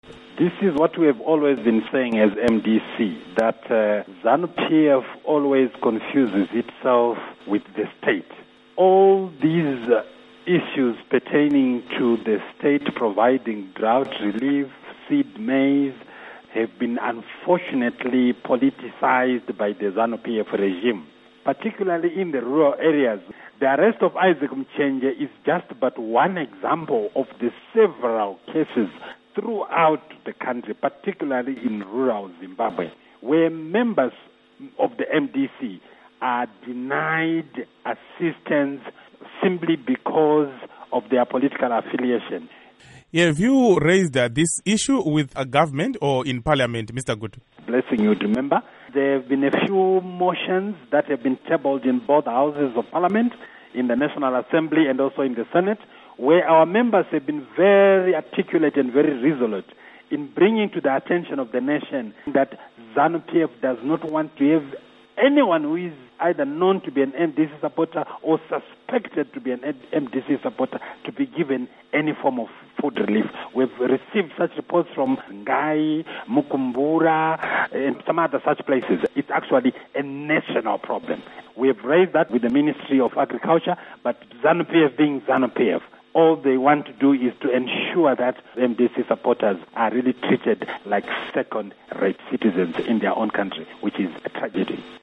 Interview With Obert Gutu on MDC-T Official Arrest